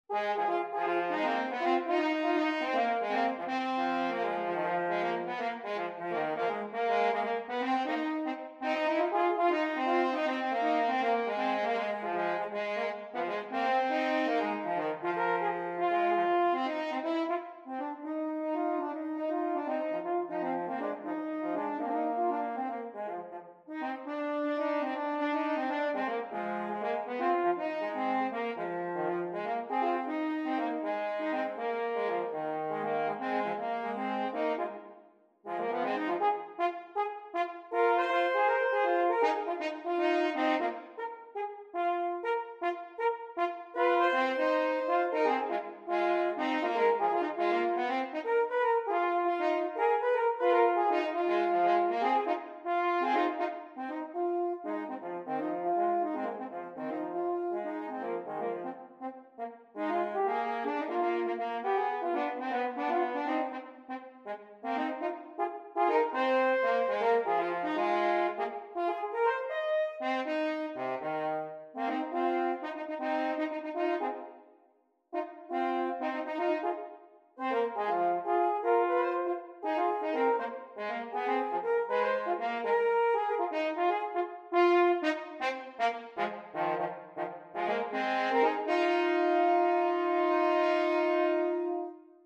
Gattung: Für 2 Hörner
Besetzung: Instrumentalnoten für Horn
Jazz-Duetten